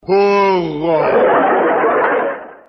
Tripa Seca do Chapolin Colorado diz: "Burro" (burrrrrooooo).